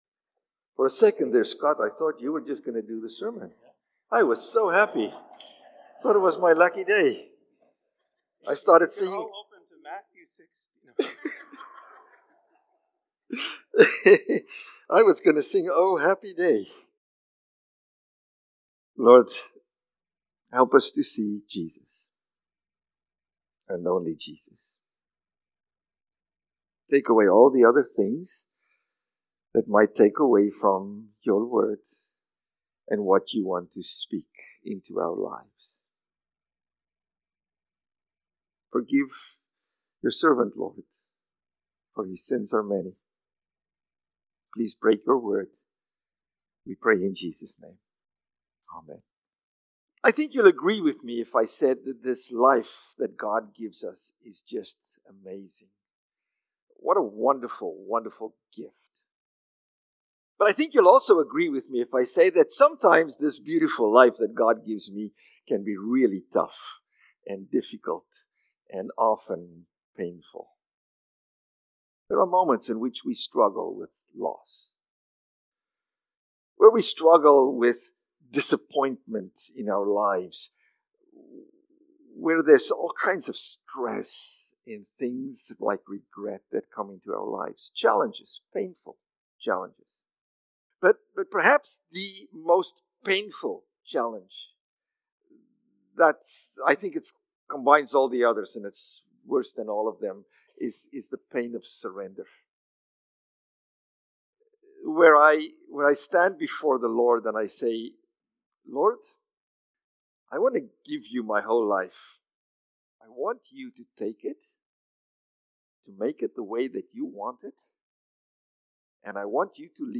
April-6-Sermon.mp3